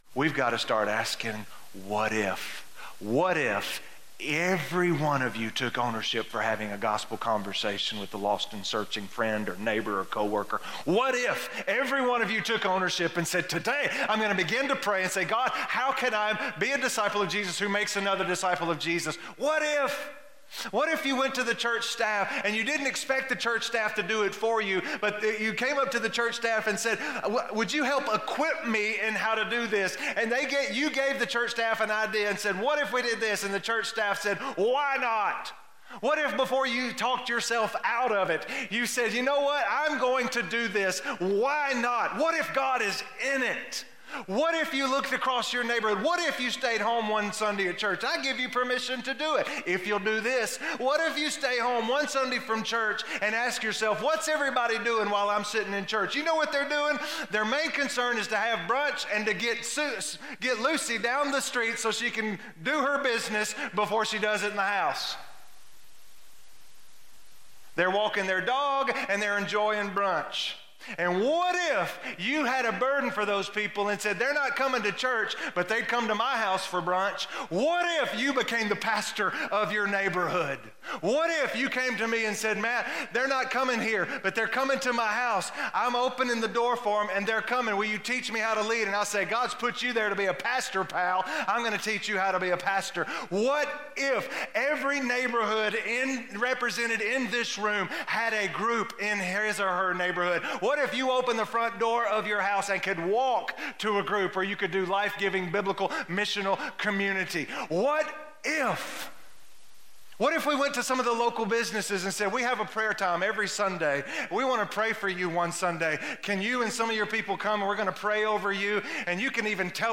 Loving God With All of My Heart - Sermon - West Franklin